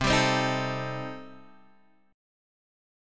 C Minor 6th